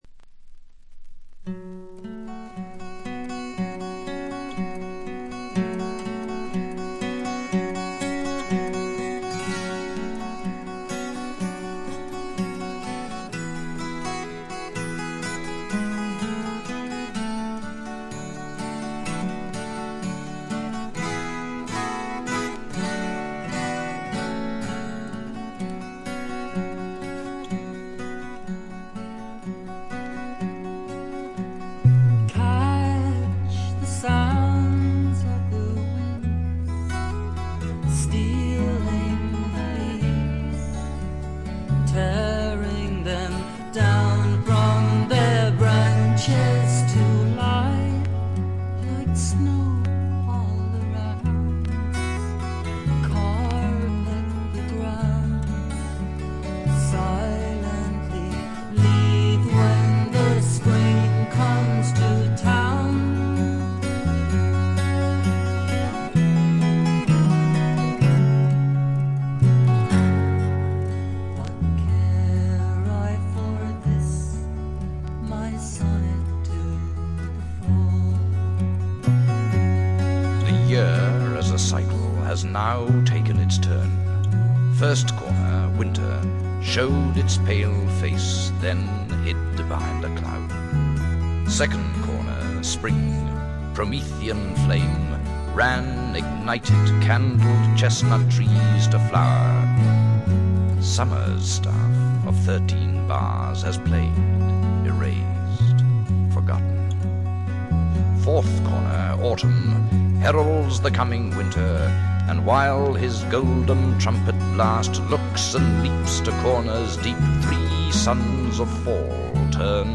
軽微なプツ音少々、静音部でわずかなチリプチ。ほとんどノイズ感無しで良好に鑑賞できると思います。
ギター、マンドリン、ダルシマー等のアコースティック楽器のみによるフォーク作品。
節々で短い詩の朗読が入りますが、これもまったく邪魔にはならず気持ちよく聴けます。
試聴曲は現品からの取り込み音源です。